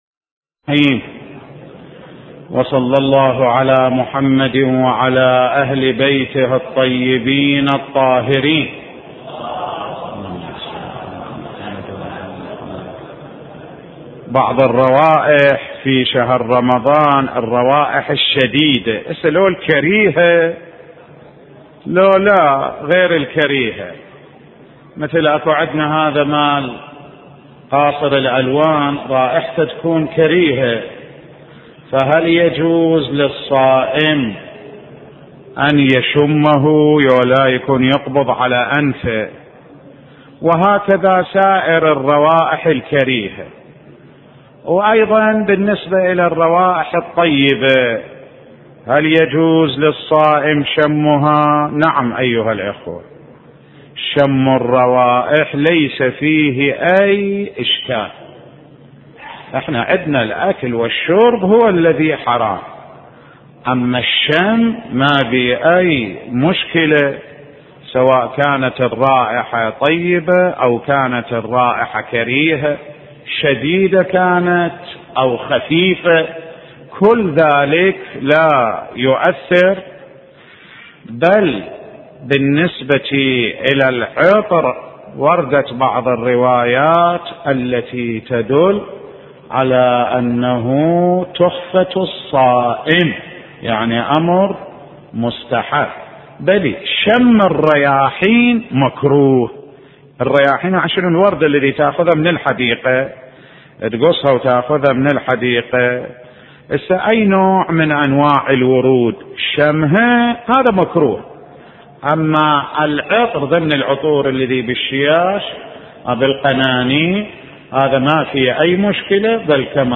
سلسلة دروس في نهج البلاغة (3)